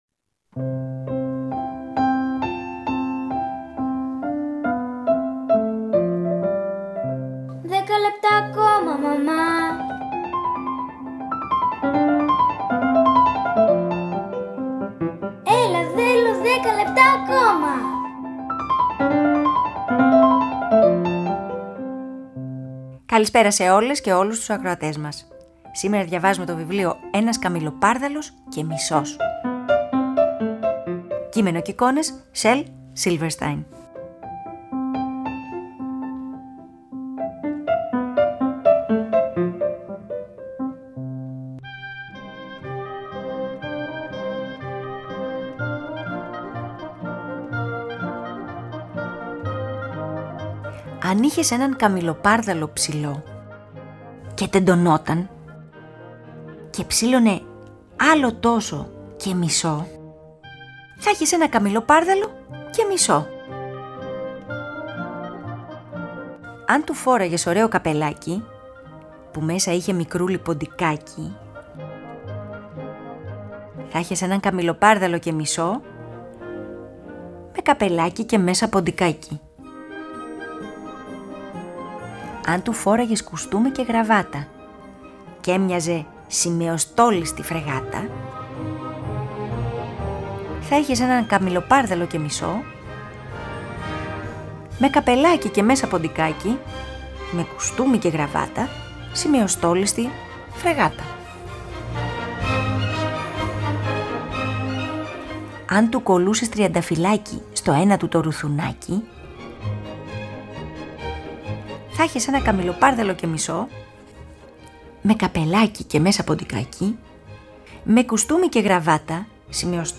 Ένα από τα πιο κλασικά και χαρακτηριστικά βιβλία του Silverstein, με έμμετρη αφήγηση και το εμβληματικό χιούμορ του συγγραφέα.
Αφήγηση-Μουσικές επιλογές